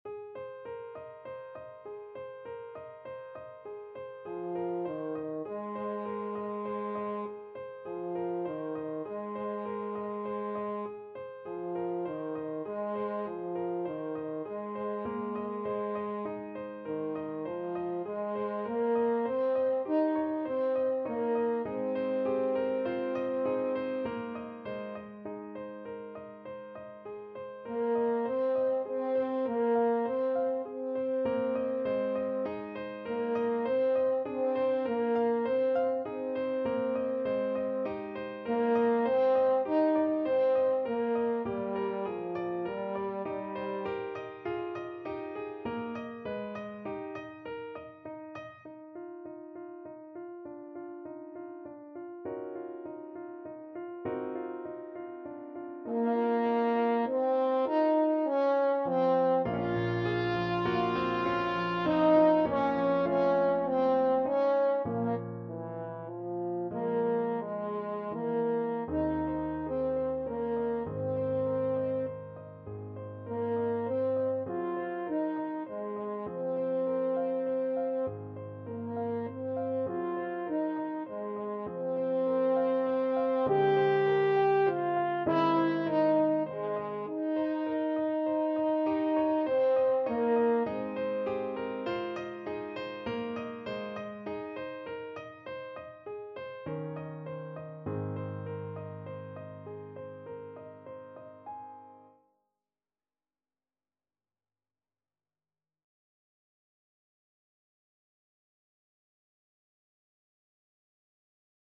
Free Sheet music for French Horn
Ab major (Sounding Pitch) Eb major (French Horn in F) (View more Ab major Music for French Horn )
9/4 (View more 9/4 Music)
~ = 120 Allegretto
Classical (View more Classical French Horn Music)
rachmaninoff_op21_5_lilacs_HN.mp3